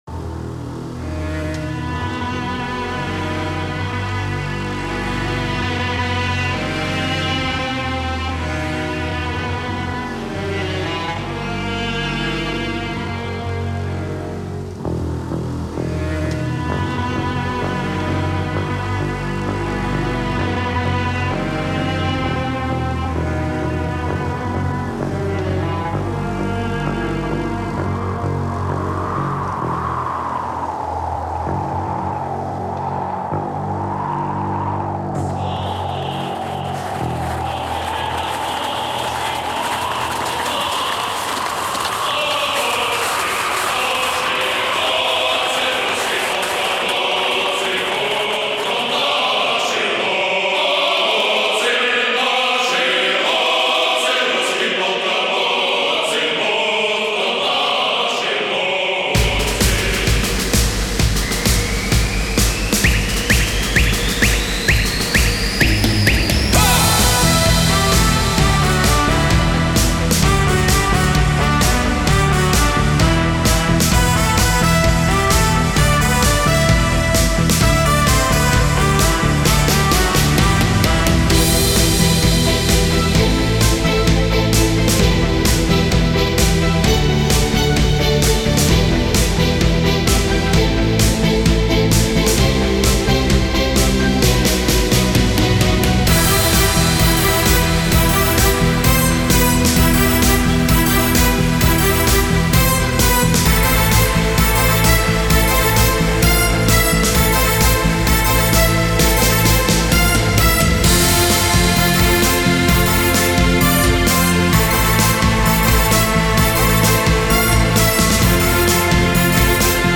Инструментальная музыка